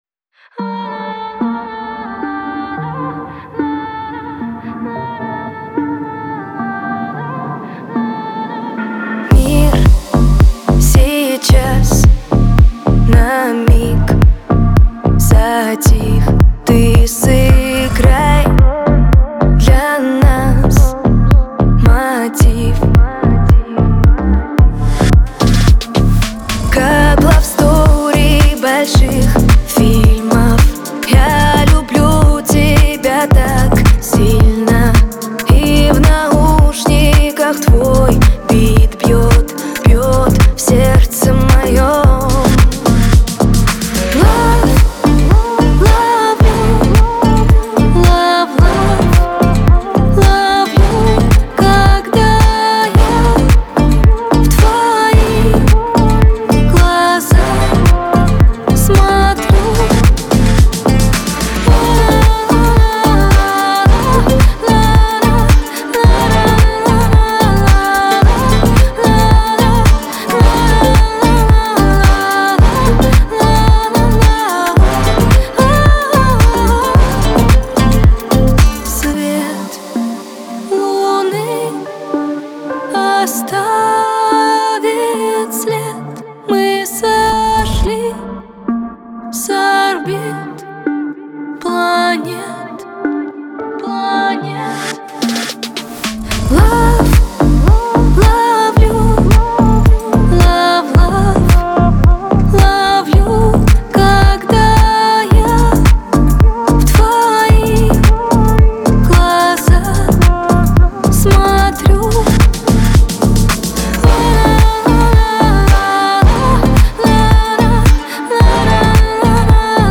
это романтичная поп-песня, наполненная теплотой и нежностью.